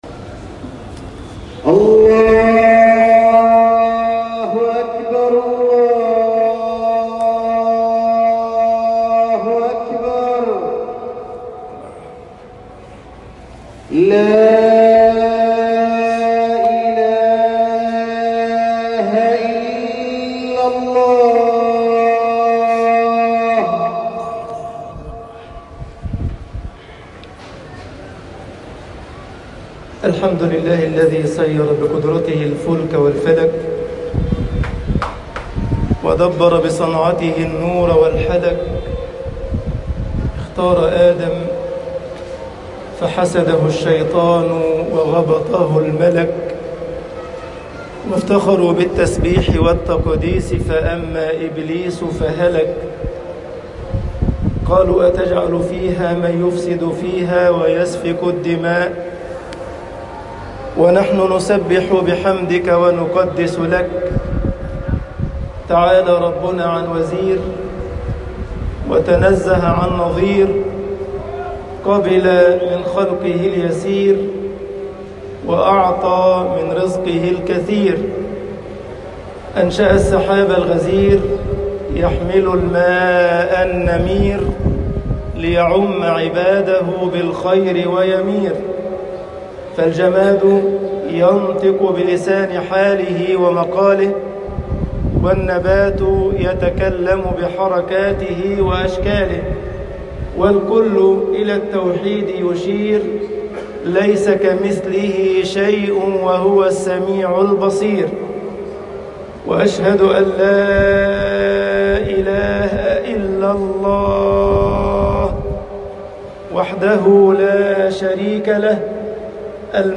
خطب الجمعة - مصر فريضة الحجِّ ذكريات وأسرار